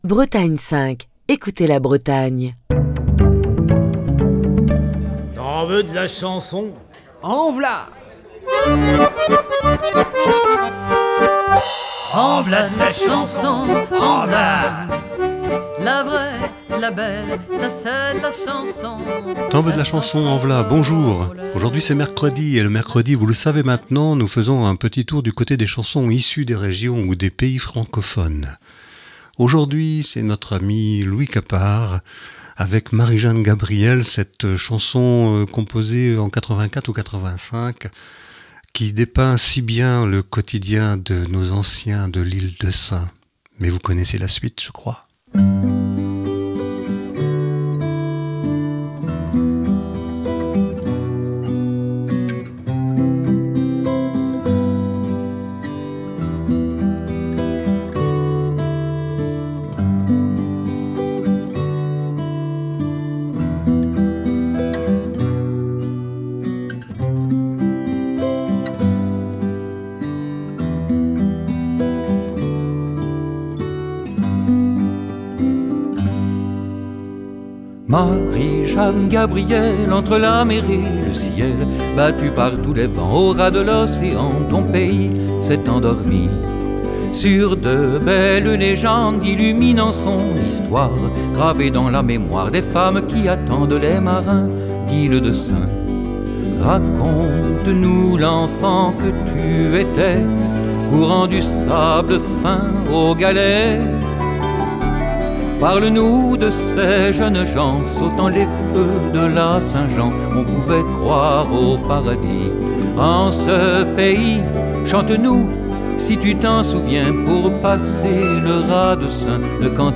Chronique du 8 mars 2023.